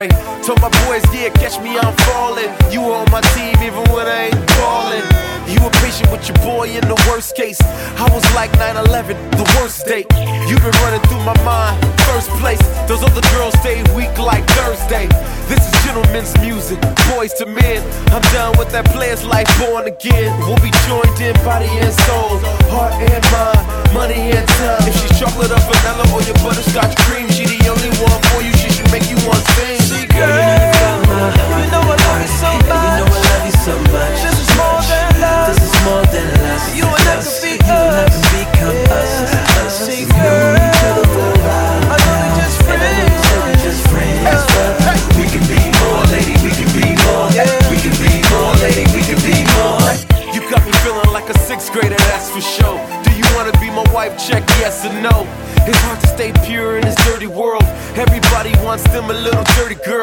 Rock-, Pop-, Motown-, Soul- und Black Gospel-Einflüsse
• Sachgebiet: Pop